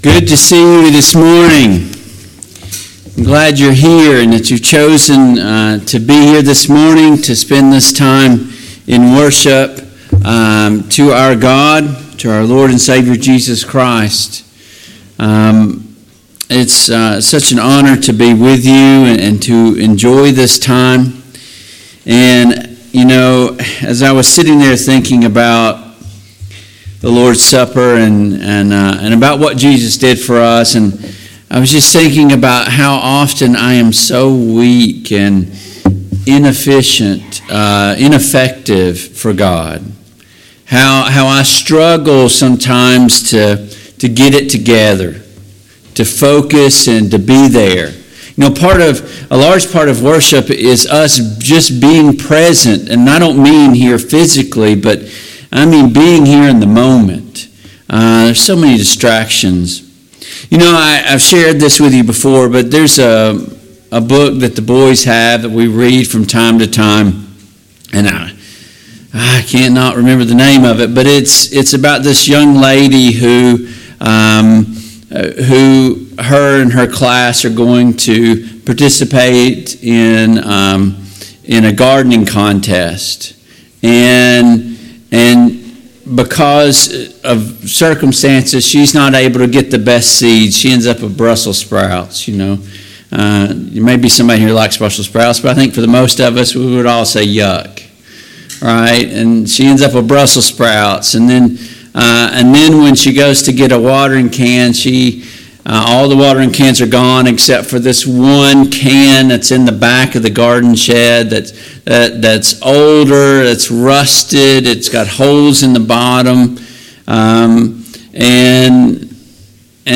Service Type: AM Worship Topics: Anxiety , Faith , gossip , Judging , Lust , Sin , Worry